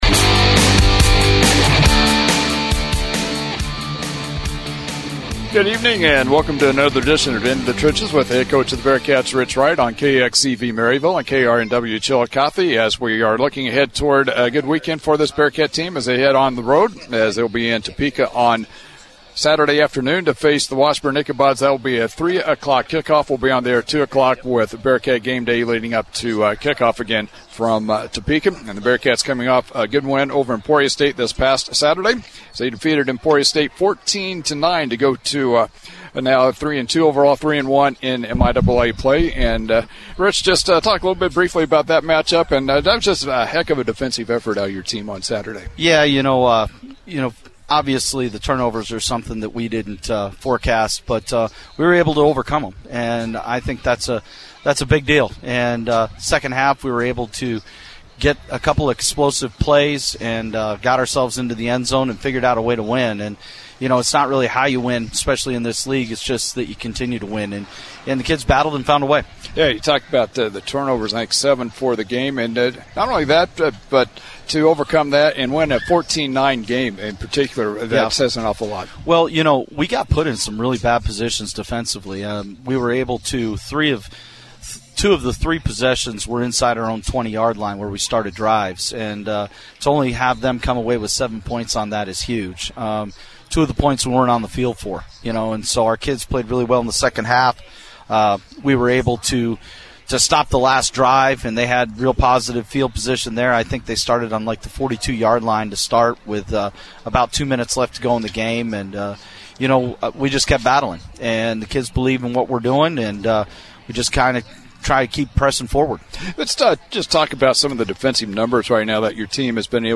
In the Trenches airs every Thursday at 6pm live from A&G Restaurant in downtown Maryville.